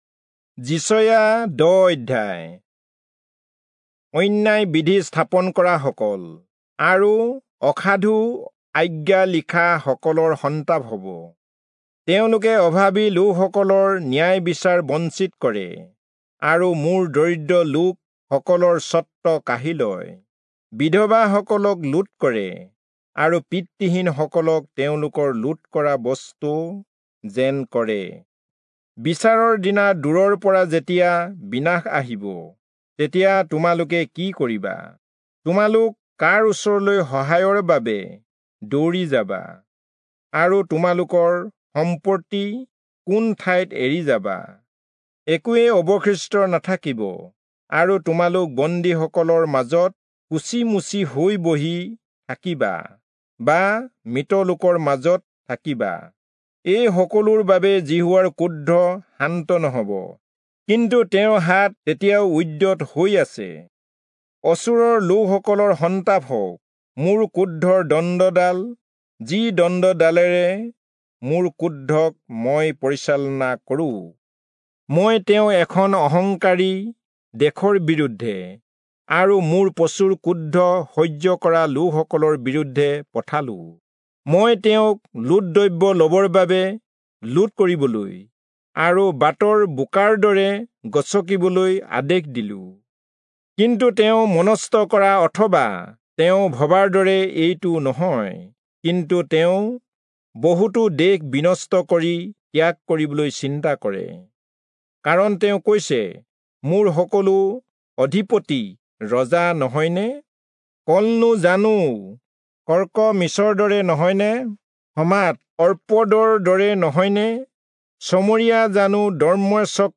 Assamese Audio Bible - Isaiah 59 in Ylt bible version